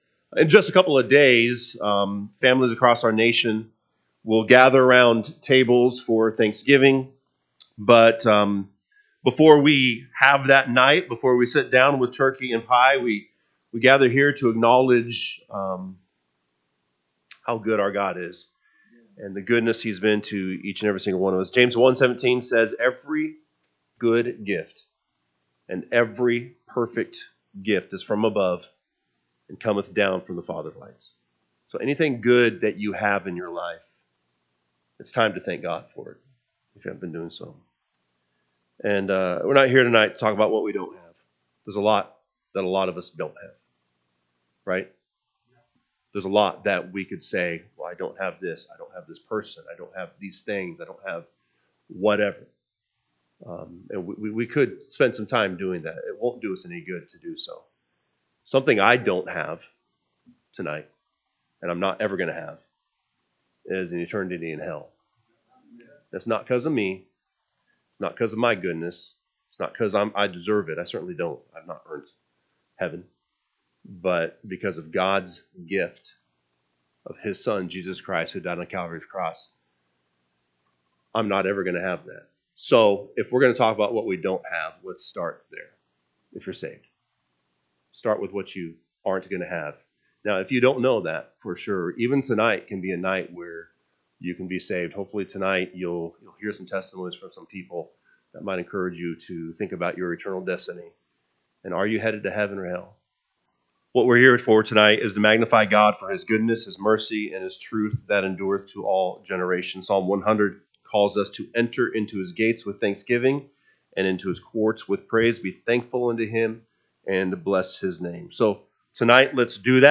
November 25, 2025 Tue. Praise and Thanksgiving Service
Please listen to the messages presented by our pastor and a few of our church members as they reflect on God’s church and what it means to them.